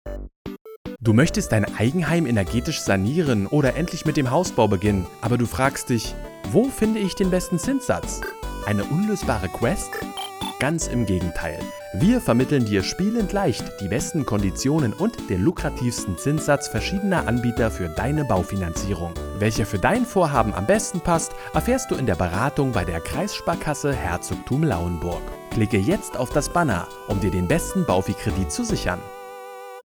Untermalt mit der richtigen Musik und den passenden Sounds überzeugt die Kampagne auf ganzer Linie.